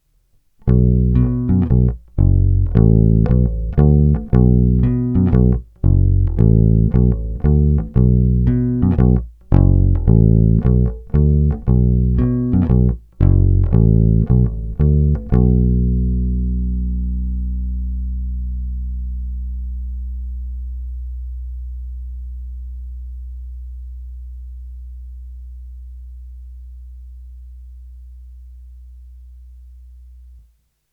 Nový snímač je víc vintage, zpět do šedesátkových let.
Není-li uvedeno jinak, nahrávky jsou provedeny rovnou do zvukovky a kromě normalizace ponechány bez úprav.
Hra mezi snímačem a krkem